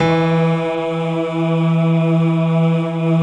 SI1 PIANO04L.wav